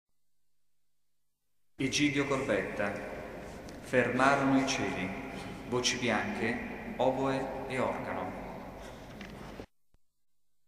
Elevazioni Musicali > 1995 > 1999
S. Alessandro in Colonna